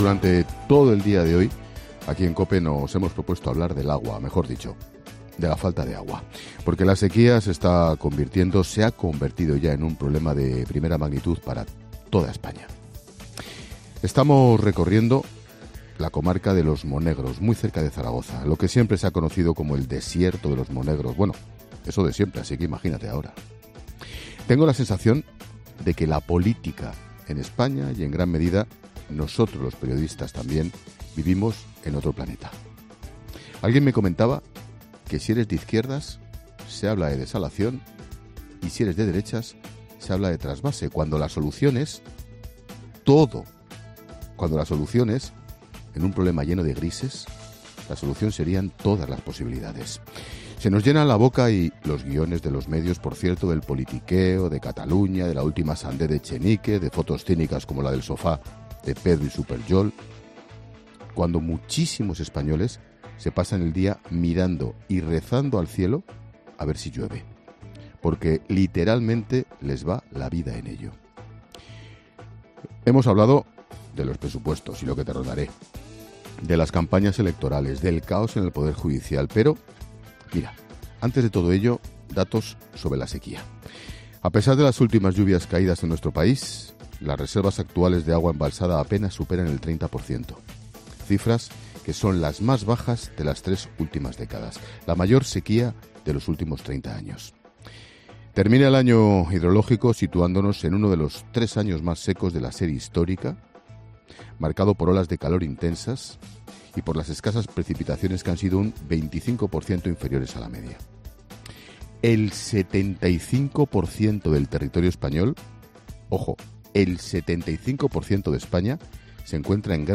Monólogo de Expósito
El director de 'La Linterna' analiza el problema de la sequía en profundidad en el monólogo de este miércoles desde Los Monegros, Zaragoza